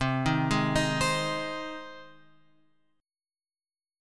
Cm#5 chord {8 6 6 8 9 x} chord